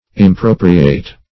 Impropriate \Im*pro"pri*ate\, v. i.